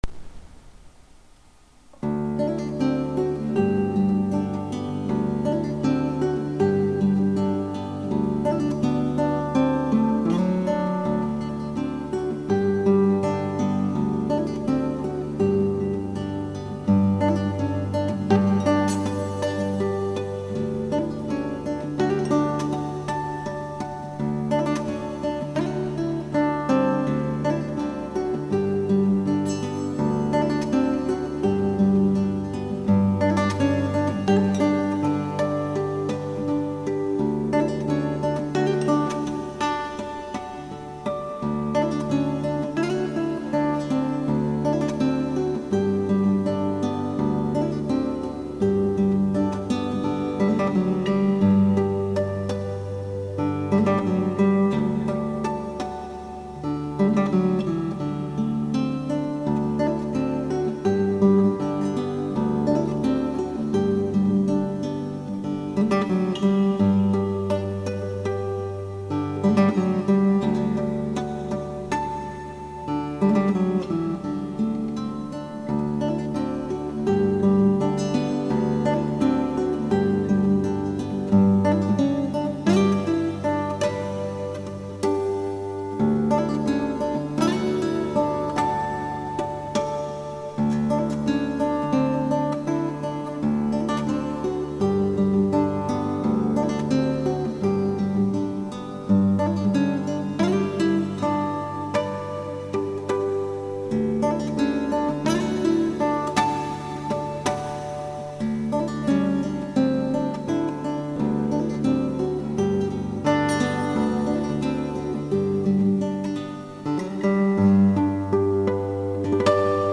for performing and teaching the art Hawaiian Slack Key at our July 9th meeting.